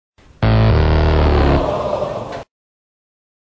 fail_old.mp3